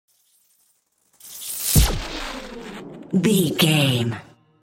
Whoosh sci fi disappear electricity
Sound Effects
futuristic
high tech
whoosh